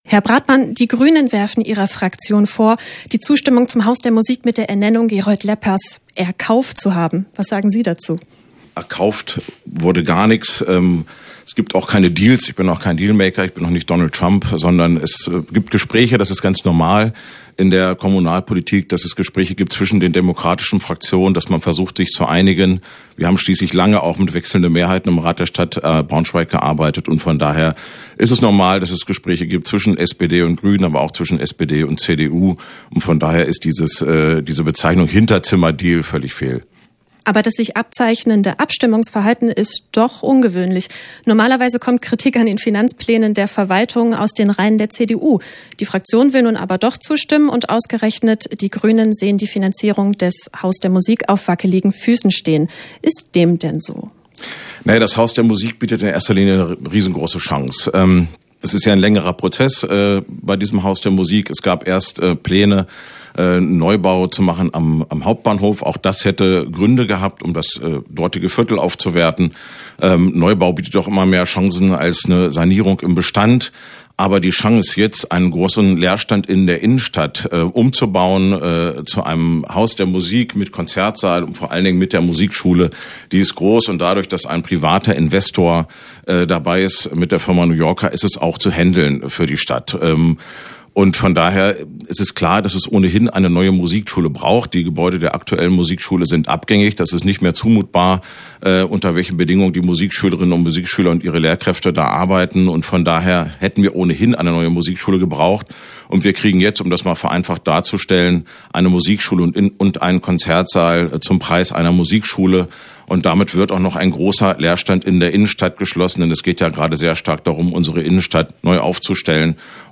„Hinterzimmer“-Kritik der Braunschweiger Grünen: Das sagt SPD-Fraktionschef Christoph Bratmann - Okerwelle 104.6
mit dem SPD-Fraktionsvorsitzenden Christoph Bratmann gesprochen.